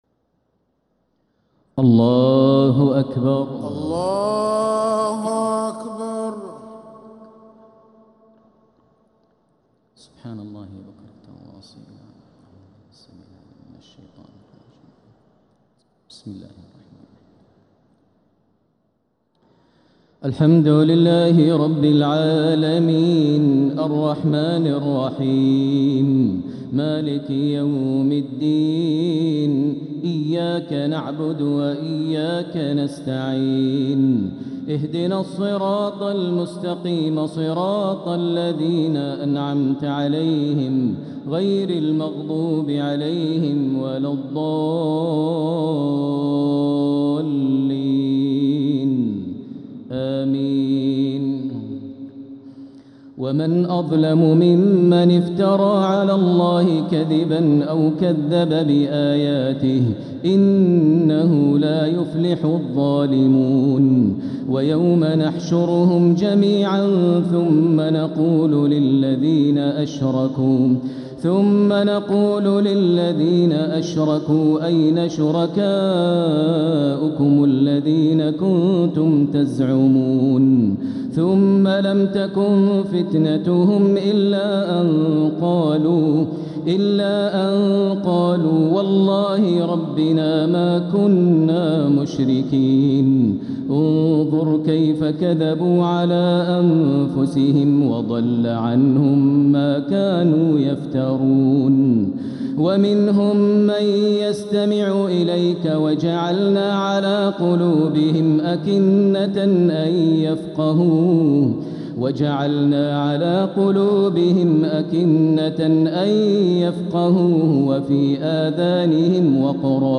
تراويح ليلة 9 رمضان 1447هـ من سورة الأنعام (21-73) | Taraweeh 9th niqht Ramadan 1447H Surat Al-Anaam > تراويح الحرم المكي عام 1447 🕋 > التراويح - تلاوات الحرمين